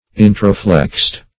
Introflexed \In`tro*flexed"\, a. Flexed or bent inward.